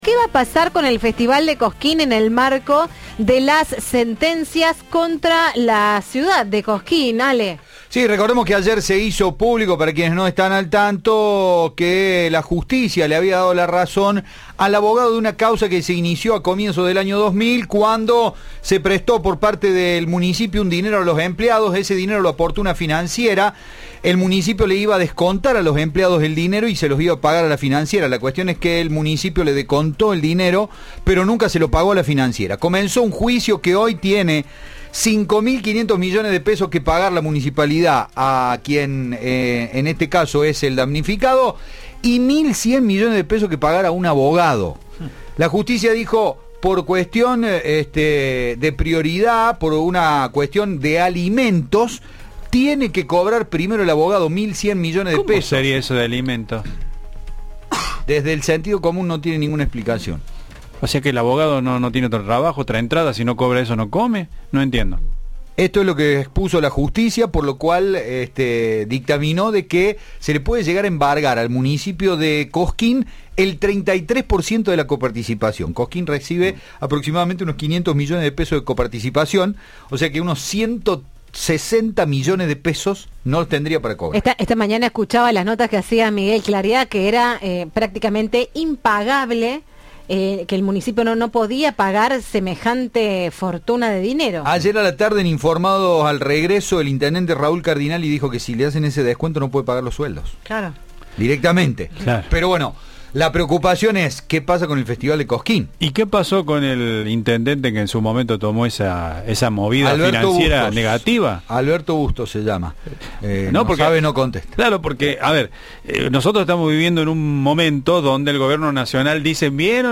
Sin embargo, el intendente Raúl Cardinale en diálogo con Cadena 3 confirmó que el festival “está completamente confirmado” y que ya se inició la construcción de una nueva terraza gastronómica en la Plaza Próspero Molina.
Informe